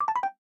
tweet_received.ogg